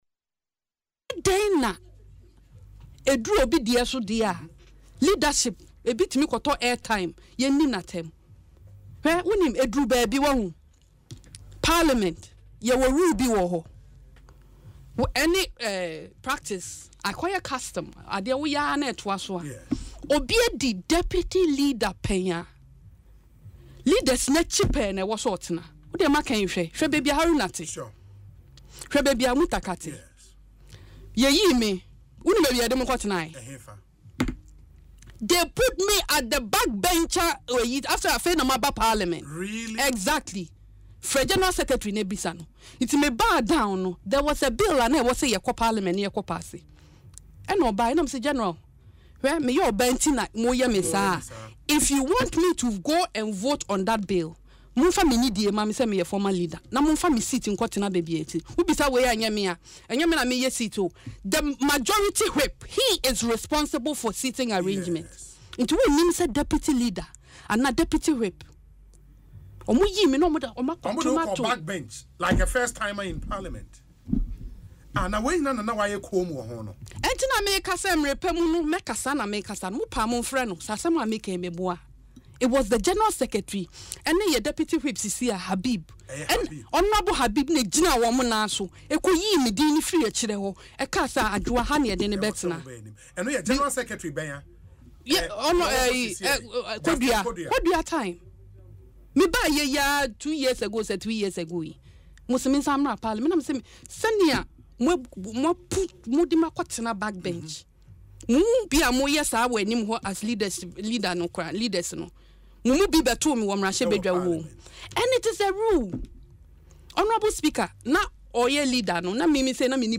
In an interview on Asempa FM’s Ekosii Sen, she disclosed that she was thrown to the backbench like a first-time MP, contrary to the statutes of the House.